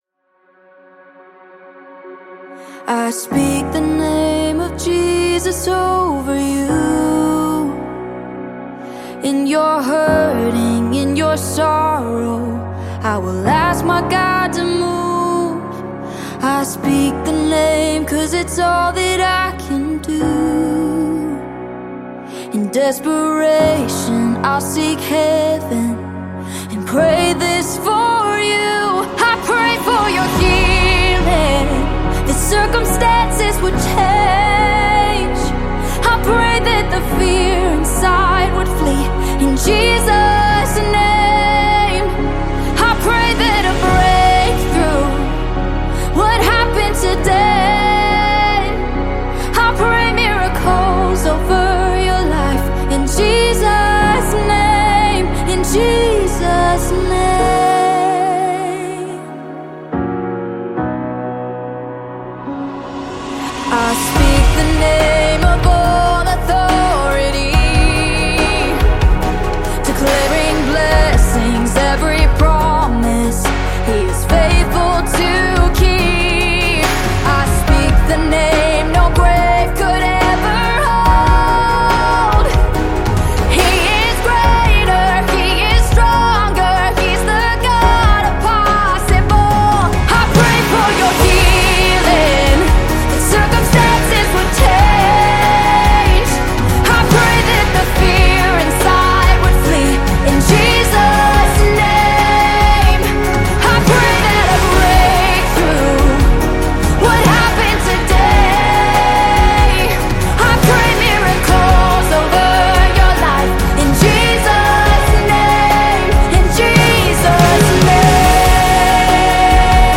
Eminent gospel music artiste